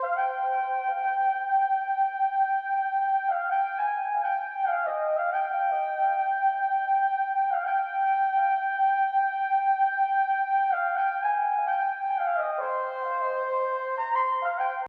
01 washy lead B2.wav